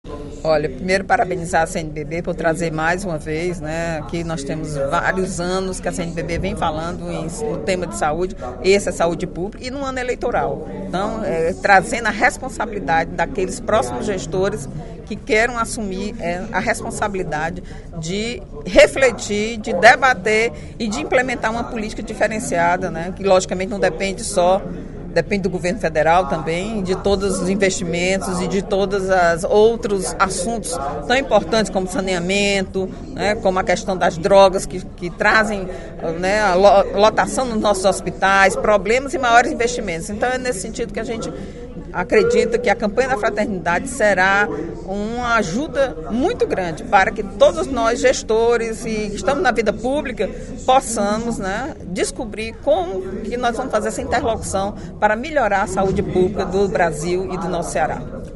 A deputada Eliane Novais (PSB) parabenizou, em pronunciamento na sessão plenária desta sexta-feira (02/03) da Assembleia Legislativa, a Confederação Nacional dos Bispos do Brasil (CNBB) pela Campanha da Fraternidade 2012.